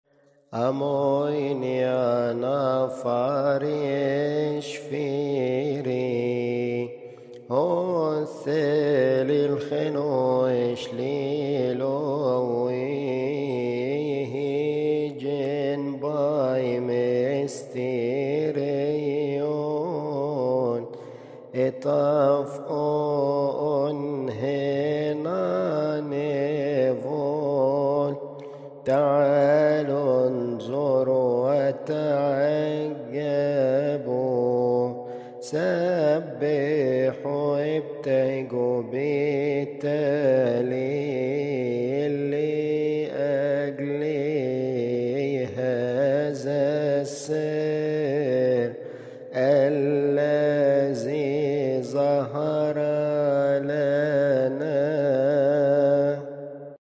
مكتبة الألحان